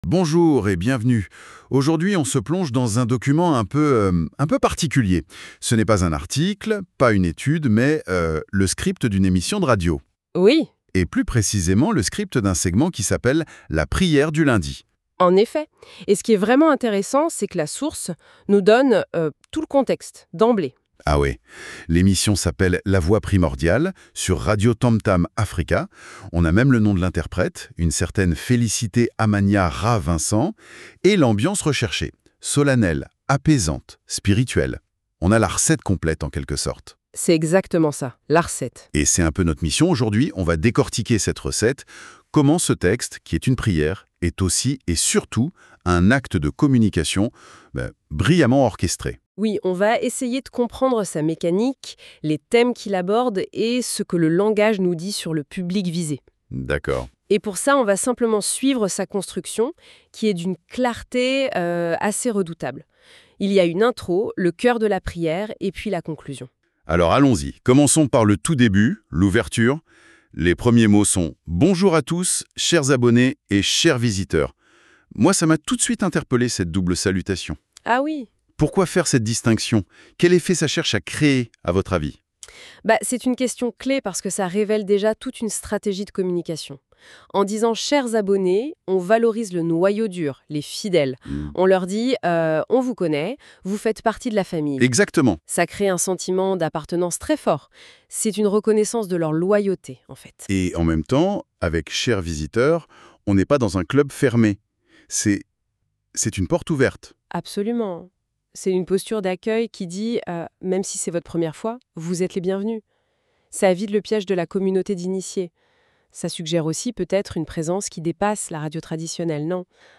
Ambiance : solennelle, apaisante, spirituelle